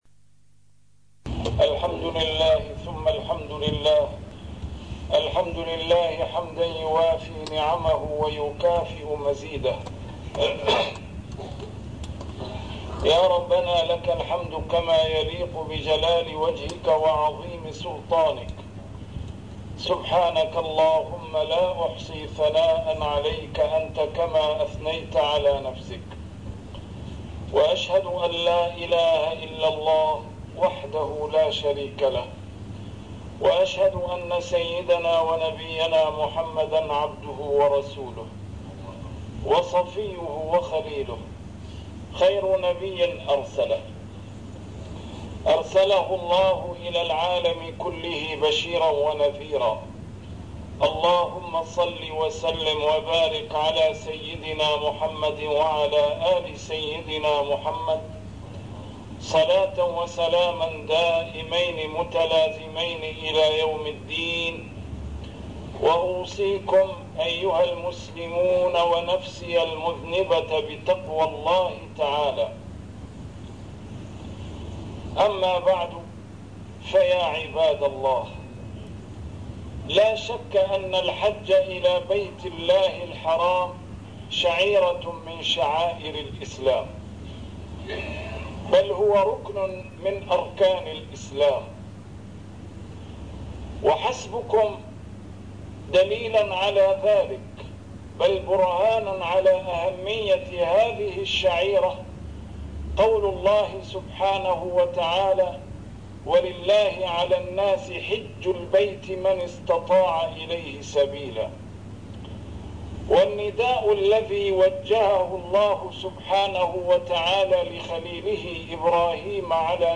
A MARTYR SCHOLAR: IMAM MUHAMMAD SAEED RAMADAN AL-BOUTI - الخطب - إلى الممنوعين من الحج هذا العام